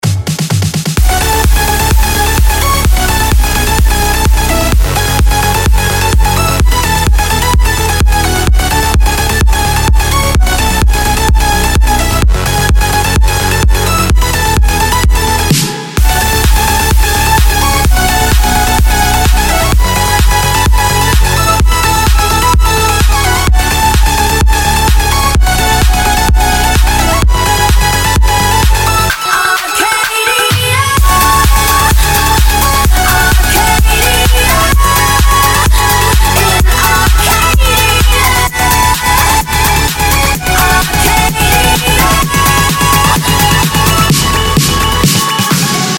• Качество: 320, Stereo
Первоклассный Электро Хаус!